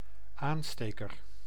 Ääntäminen
Synonyymit barge cigarette lighter Ääntäminen US Haettu sana löytyi näillä lähdekielillä: englanti Käännös Ääninäyte Substantiivit 1. aansteker {m} Lighter on sanan light komparatiivi.